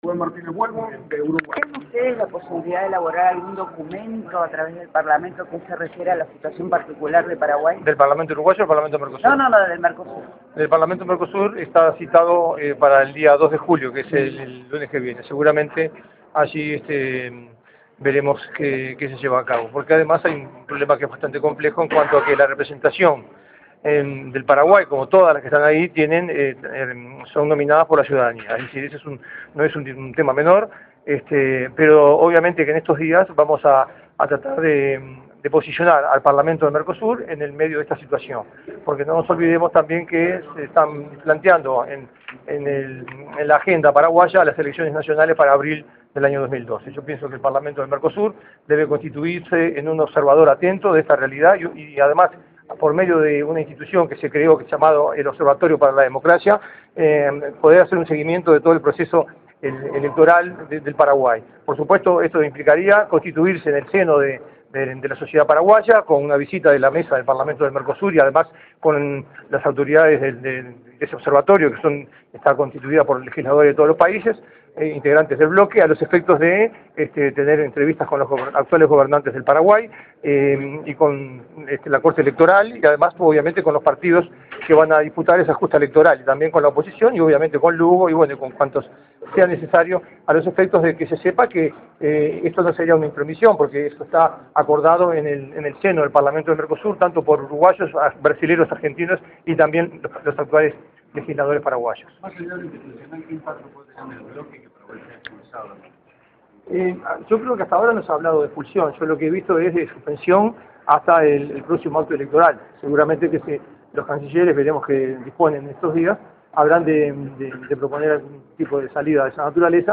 Entrevista al Parlamentario uruguayo Ruben Martinez Huelmo durante la Cumbre de Presidentes del MERCOSUR, realizada en Mendoza, Argentina, el 25/06/12.